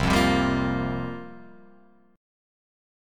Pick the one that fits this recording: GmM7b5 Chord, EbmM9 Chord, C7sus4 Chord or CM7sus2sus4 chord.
C7sus4 Chord